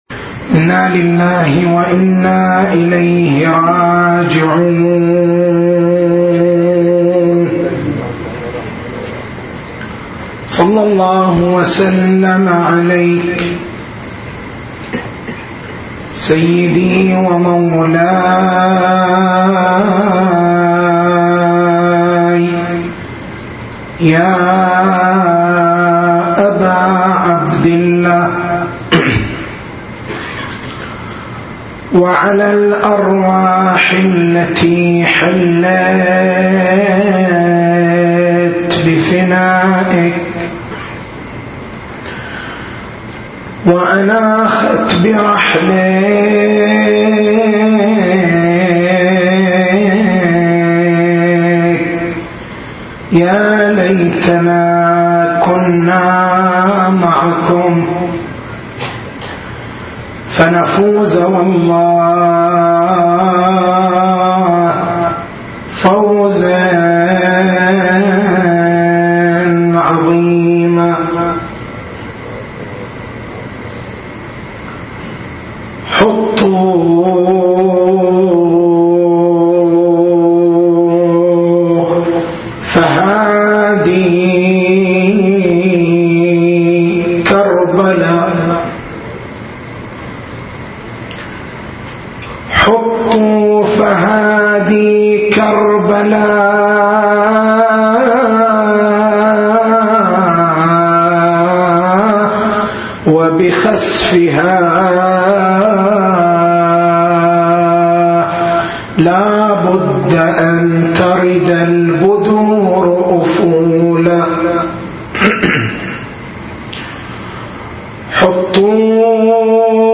هذه المحاضرة